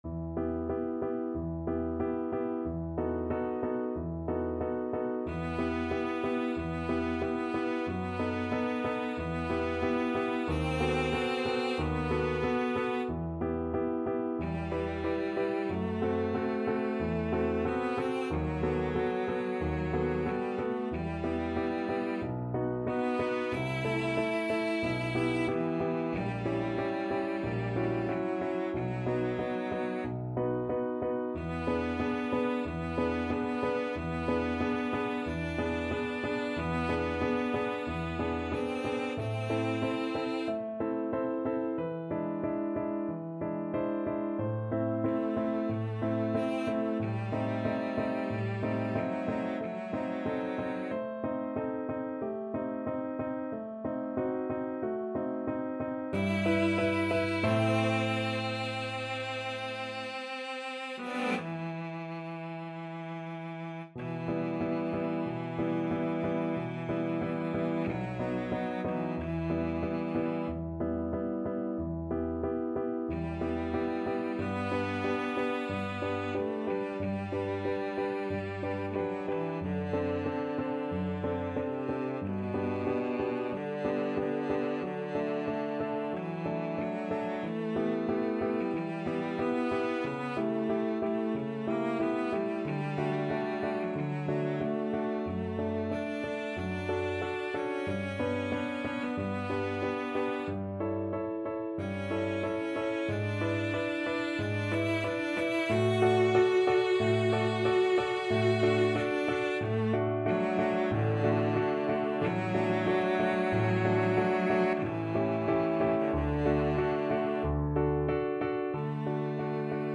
Cello
E minor (Sounding Pitch) (View more E minor Music for Cello )
Moderato = 46
4/4 (View more 4/4 Music)
Classical (View more Classical Cello Music)